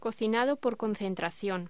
Locución: Cocinado por concentración
voz